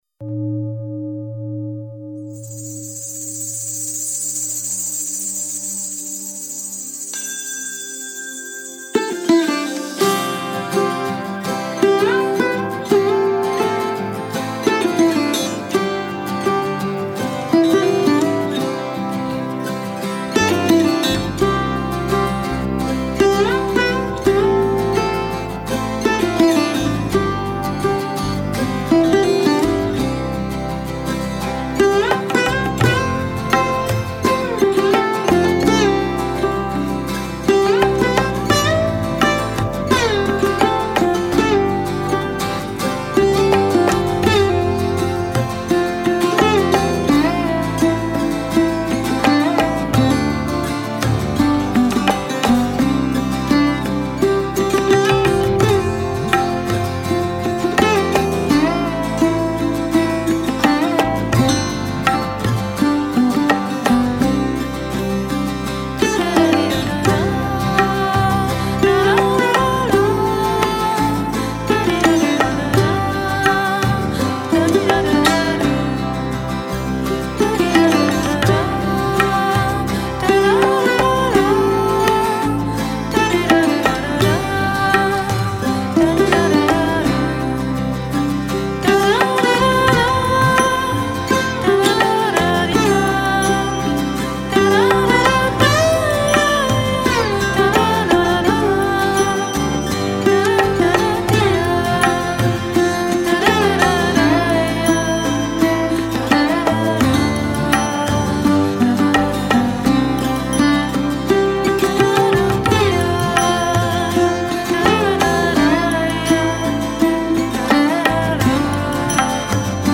NEWAGE / 心灵音乐
同时出现的还有以竹笛、塔不拉、吉他，人声，营造出平静祥和、博大、神秘的空间